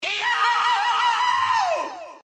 Peppino Scream Sound Button - Free Download & Play
Games Soundboard1,762 views